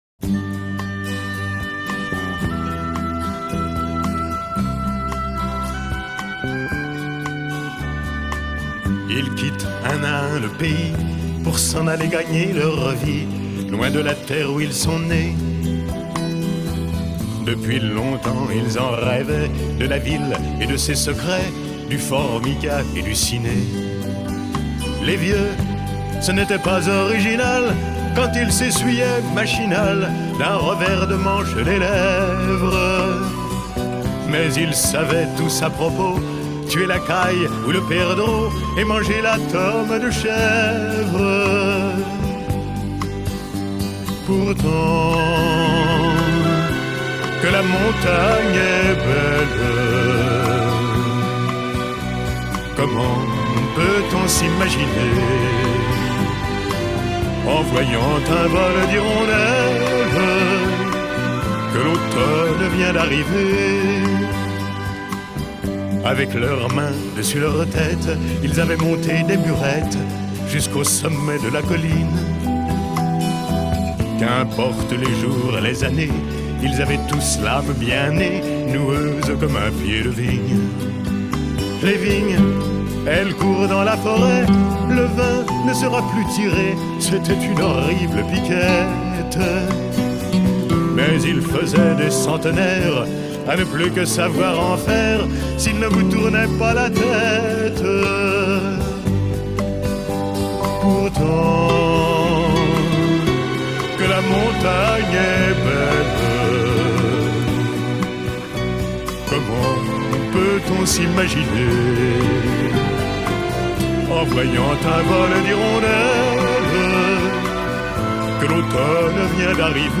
Chœur d’hommes fondé en 1860